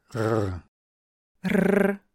Klang klar rollend, vibrierend und immer deutlich hörbar oft gurgelnd oder fast stumm (vokalisiert) wie in „Jahr“, „wir“ oder „Kinder“.
Höre dir hier an, wie unterschiedlich der R-Laut in beiden Sprachen klingt:
🔊 Hörprobe: Deutsches vs. russisches R (direkter Vergleich)